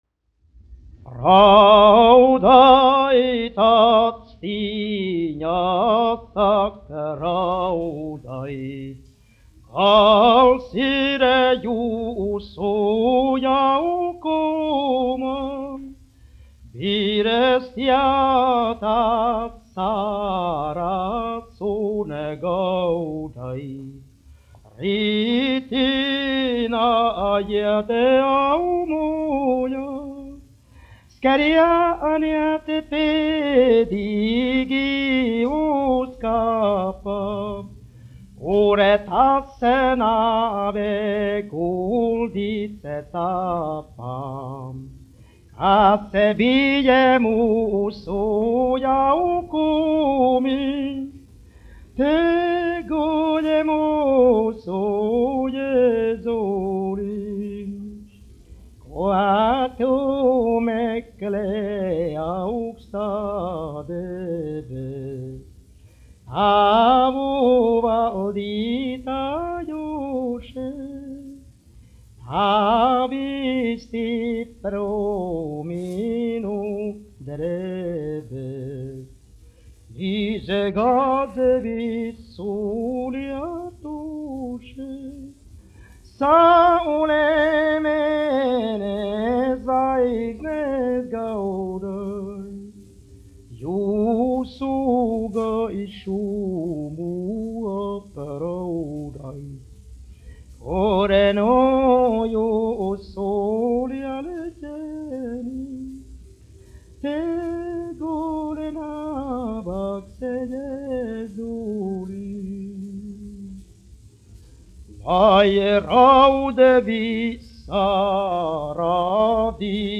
Raudait, actiņas : korāļa pārveidojums
dziedātājs
1 skpl. : analogs, 78 apgr/min, mono ; 25 cm
Garīgās dziesmas
Latviešu tautasdziesmas
Skaņuplate